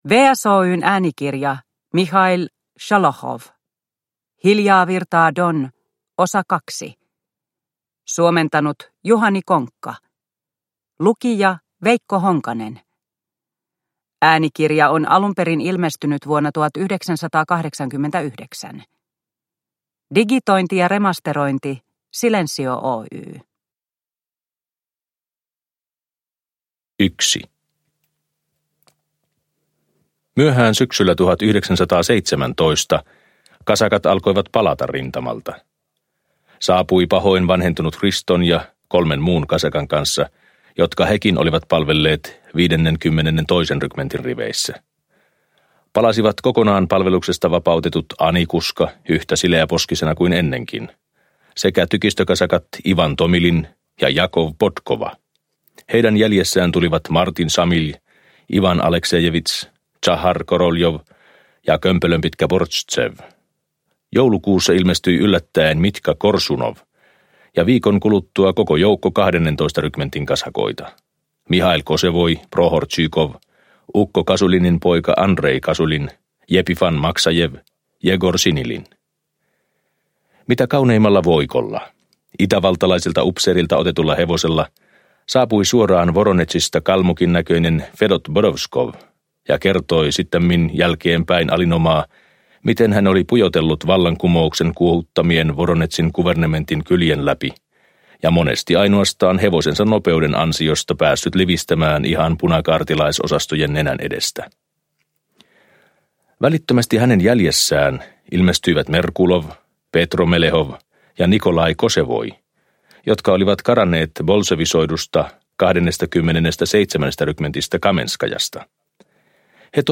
Venäläiseen kirjallisuuteen erikoistuneen Juhani Konkan suomennos on julkaistu äänikirjana vuosina 1988–89.